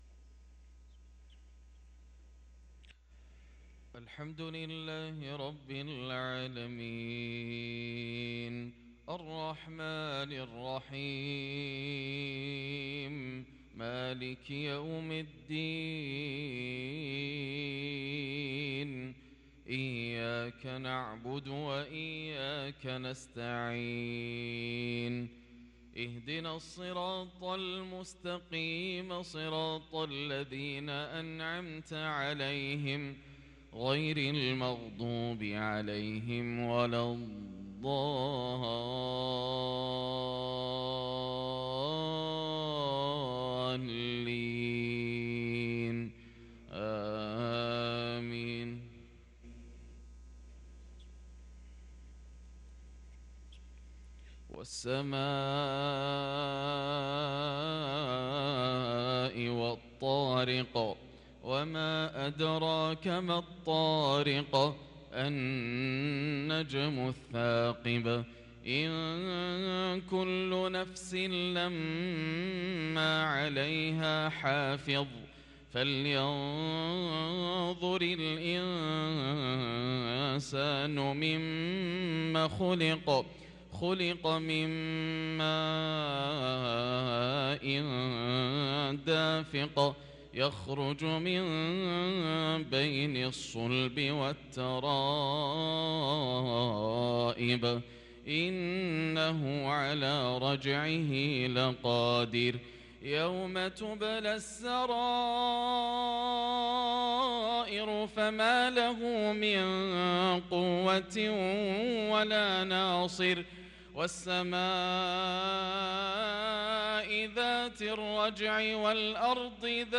صلاة المغرب للقارئ ياسر الدوسري 6 ربيع الآخر 1444 هـ
تِلَاوَات الْحَرَمَيْن .